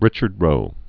(rĭchərd rō)